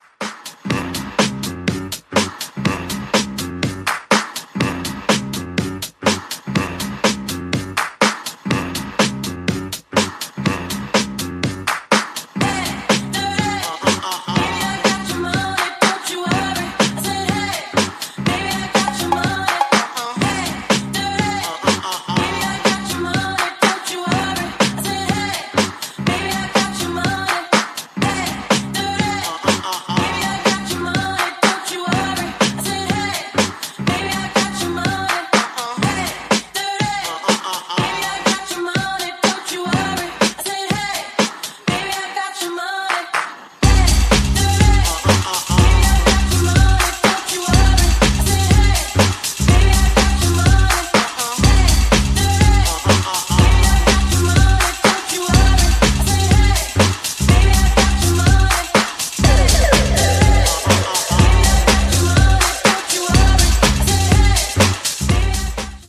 ジャンル(スタイル) HOUSE / RE-EDIT